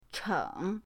cheng3.mp3